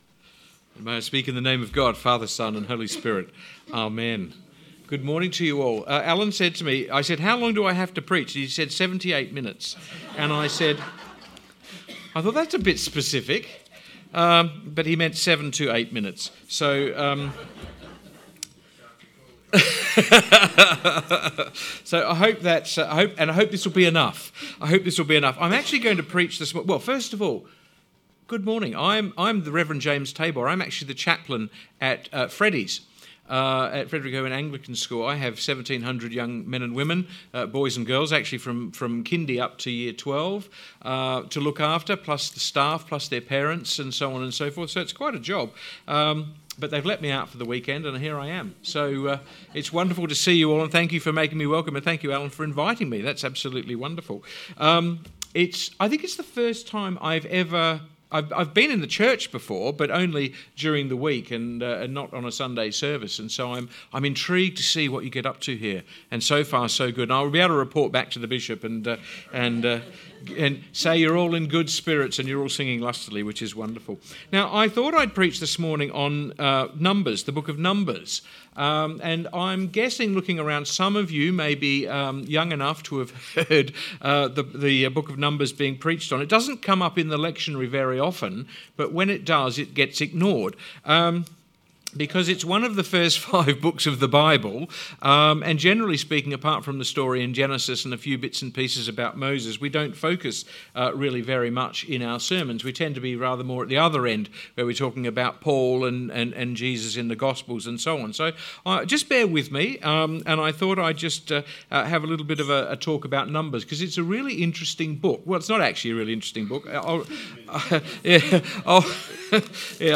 Sermon 10th March